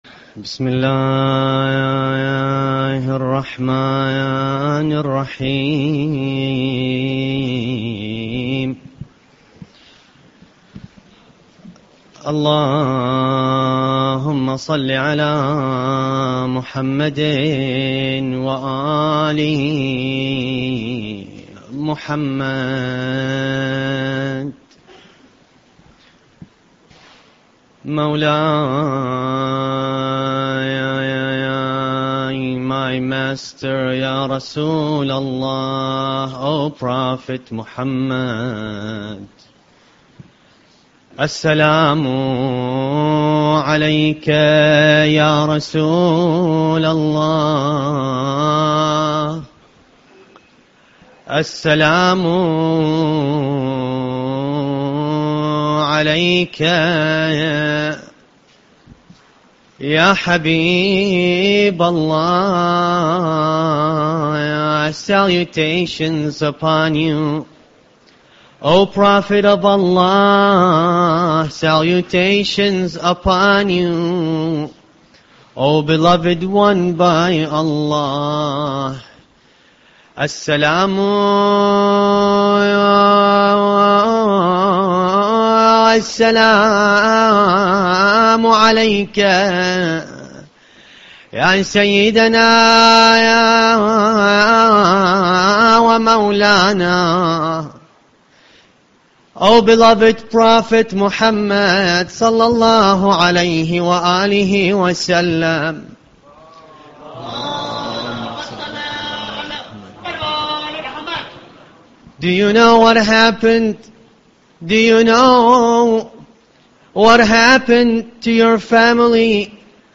Ramadan Lecture 10